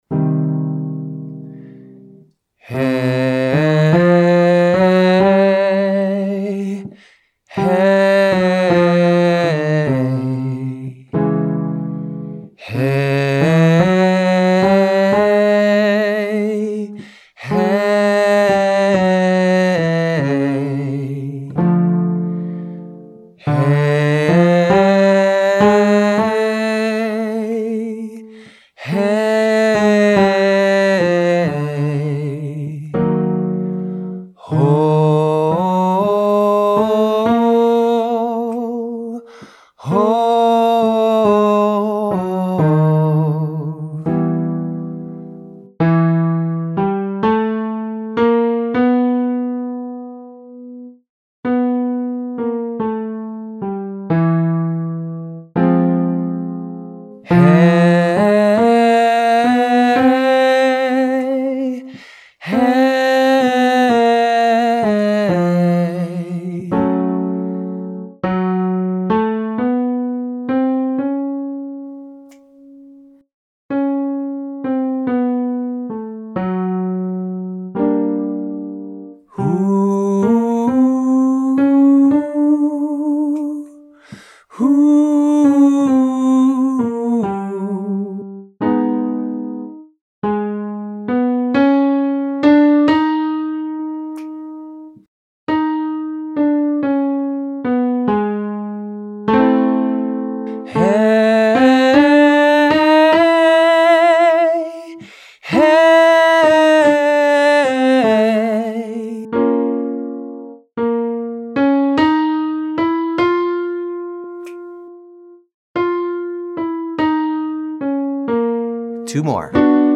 Basic Blues Scale with Call & Response
Follow along as the instructor sings the first half of the blues scale going up. He will say “blue” when we get to the “blue-note” and then come back down.
We will then move on to a call and response section where the instructor sings a phrase and you repeat it back.
Minor Blues scale on HEY HO HOO 1-b3-4-b5-5, 5-b5-4-b3-1